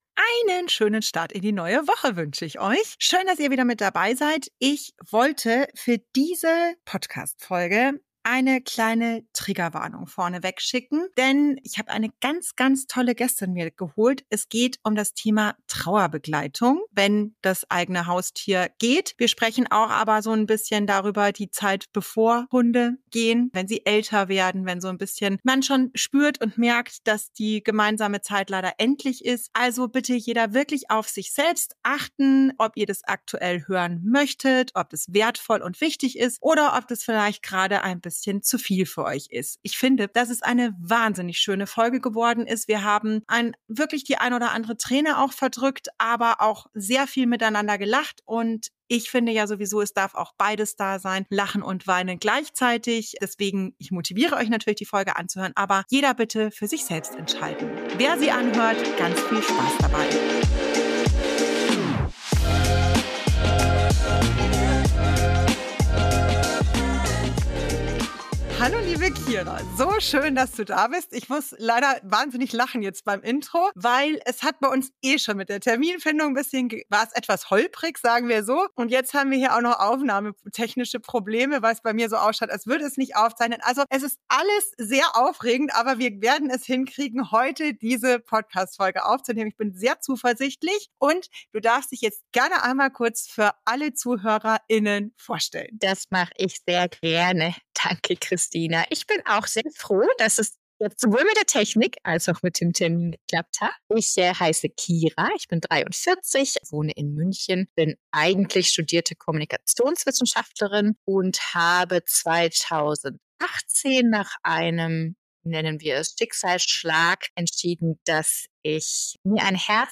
Wir haben gelacht, geweint und teilen unsere Erfahrungen und Erkenntnisse mit dir.